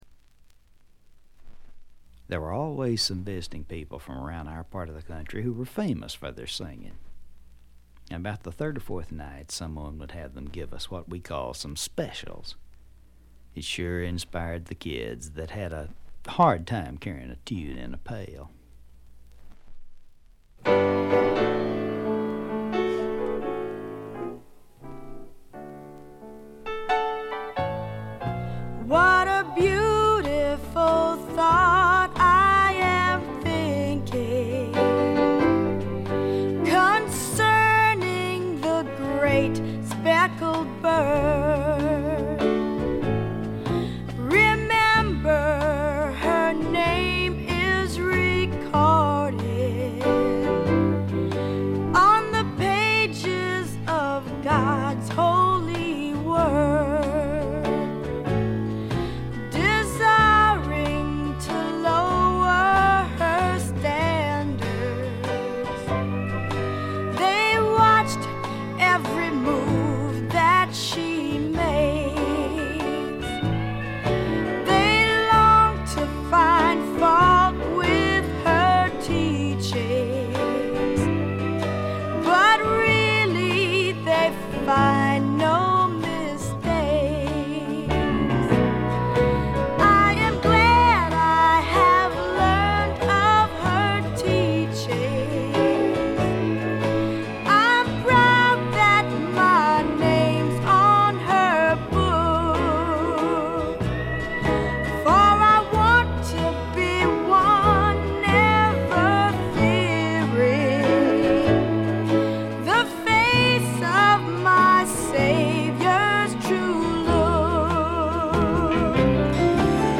微細なバックグラウンドノイズ程度。
知る人ぞ知るゴスペル・スワンプの名作！
リードシンガーは男２、女２。
試聴曲は現品からの取り込み音源です。